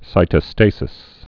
(sītə-stāsĭs, -stăsĭs)